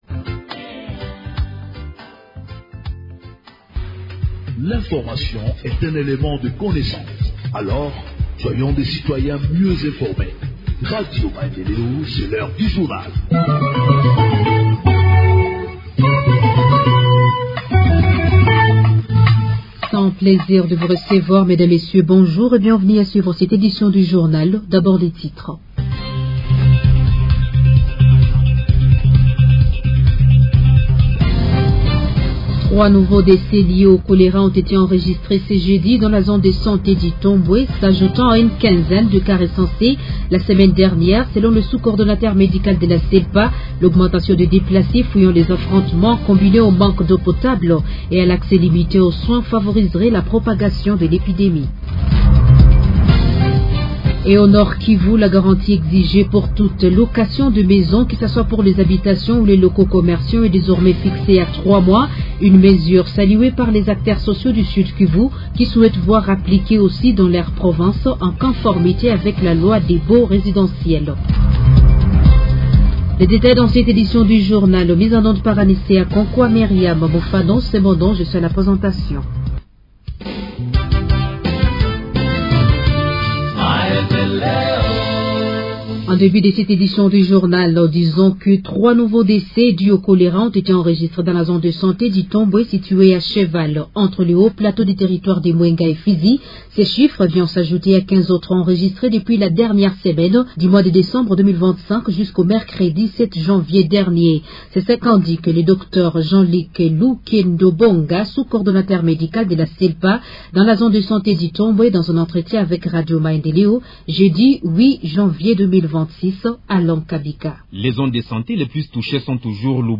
Journal en Français du 09 Janvier 2026 – Radio Maendeleo